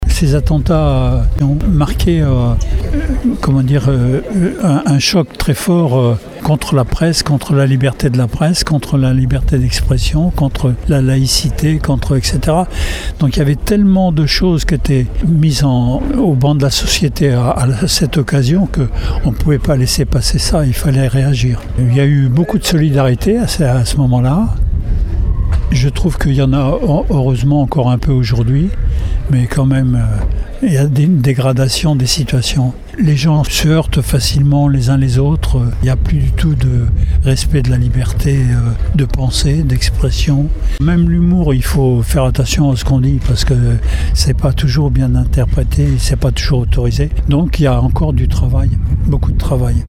À ses côtés, l’ancien maire de La Tremblade Jean-Pierre Tallieu a tenu à revenir sur cette place, qu’il avait lui-même inaugurée quelques jours seulement après les attentats du 7 janvier 2015, pour continuer à défendre la liberté d’expression :